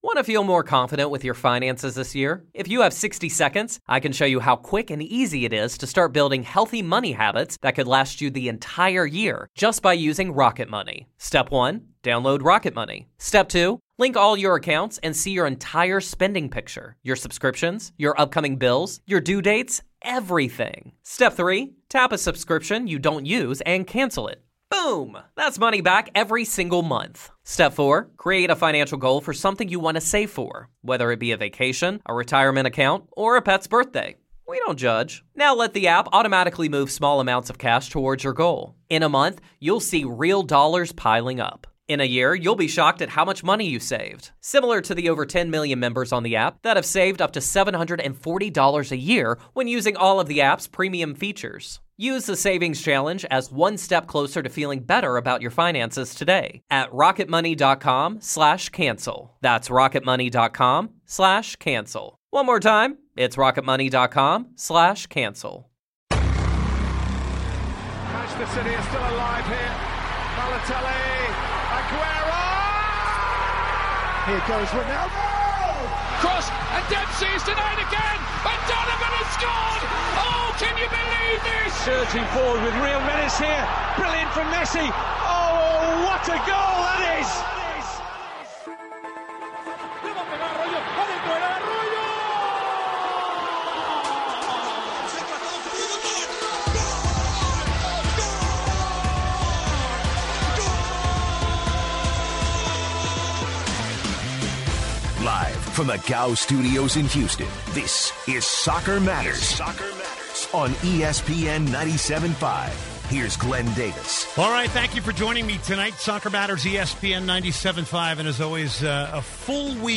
Callers weigh in on Barcelona, Dynamo over Portland, Orlando City SC, and the pressure at FC "Hollywood" Bayern Munich. The hour finishes with Liverpool's Steven Gerrard's emotional words with supporters at Anfield in his final match there.